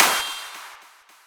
002_snares7.wav